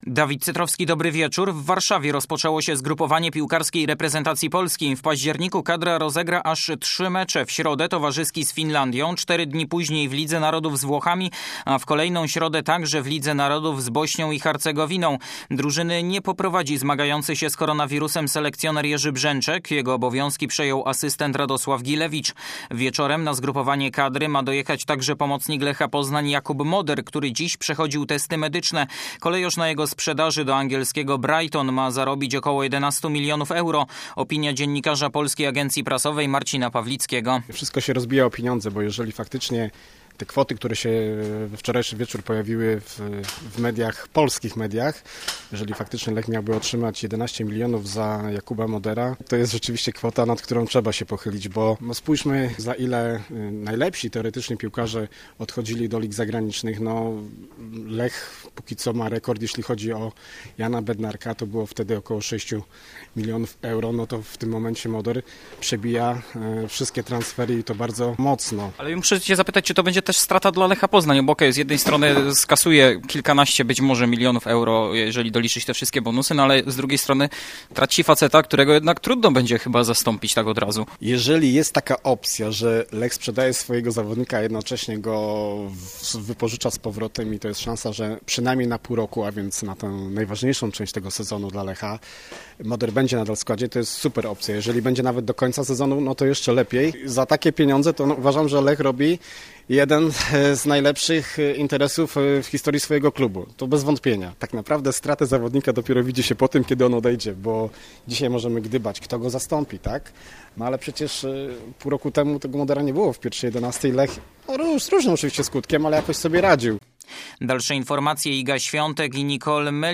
05.10. SERWIS SPORTOWY GODZ. 19:05